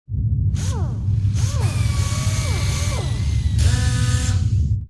Repair2.wav